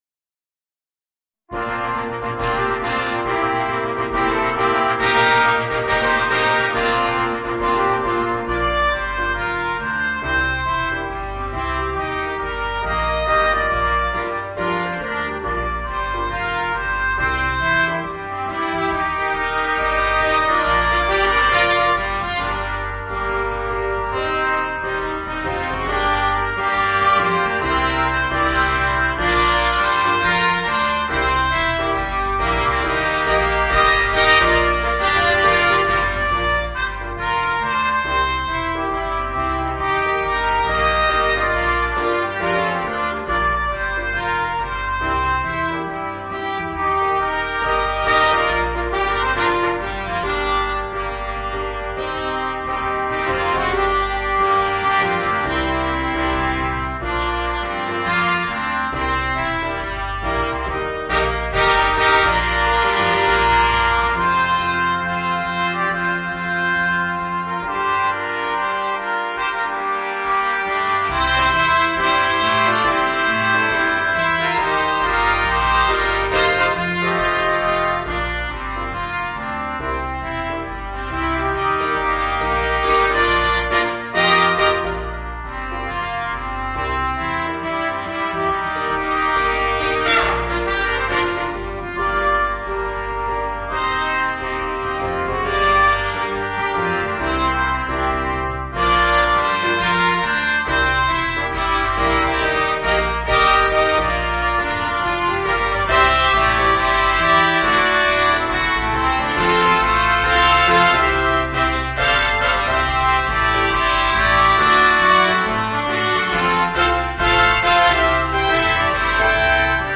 Voicing: 2 Trumpets, Horn, Trombone and Drums Level